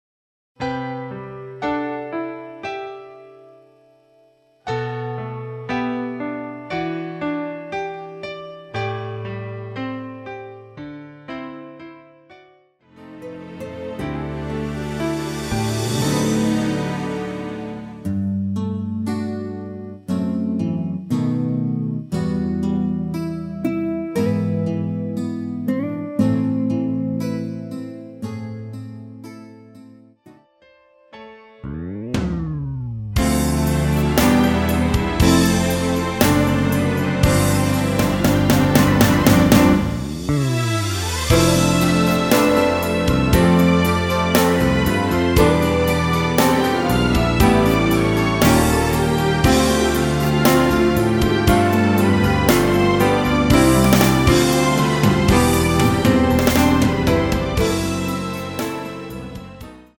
키 G 가수
원곡의 보컬 목소리를 MR에 약하게 넣어서 제작한 MR이며